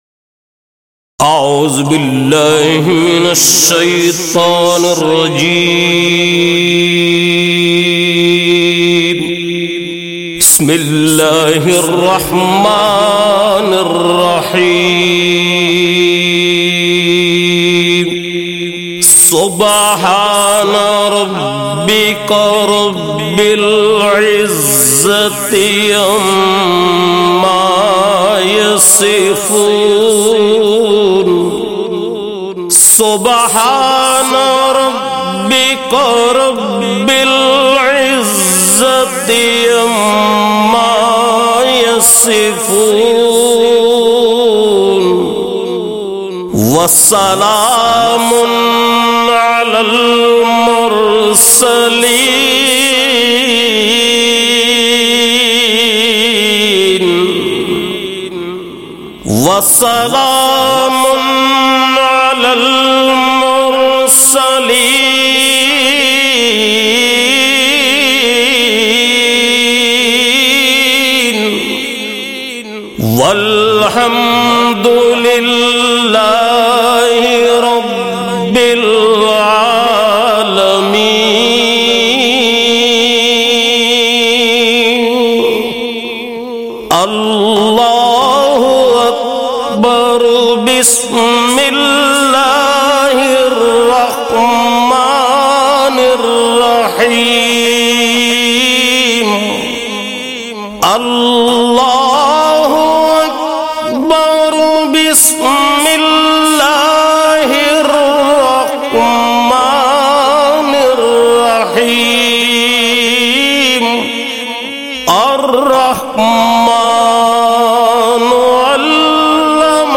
Surah e Rehman Tilawat by Siddiq Ismail MP3 & Video.mp3